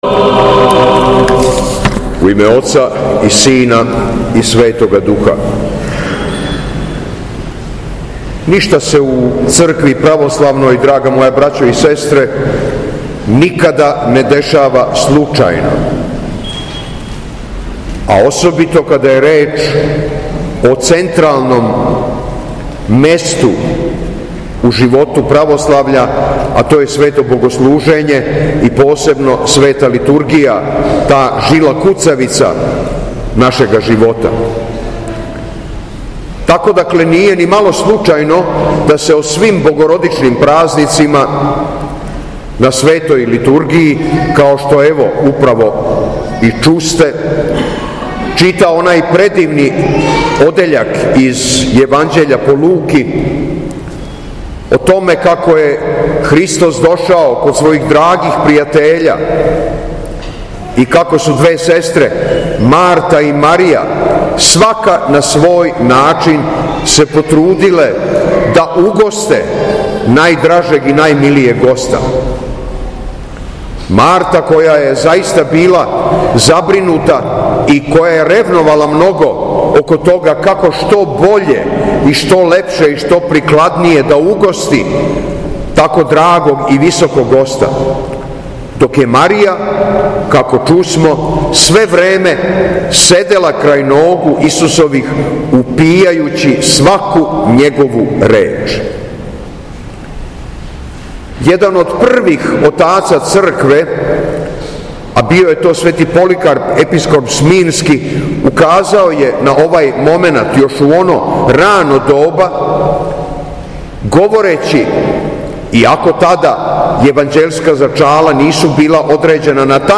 УСПЕЊЕ ПРЕСВЕТЕ БОГОРОДИЦЕ – СЛАВА САБОРНОГ ХРАМА У КРАГУЈЕВЦУ- - Епархија Шумадијска
Беседа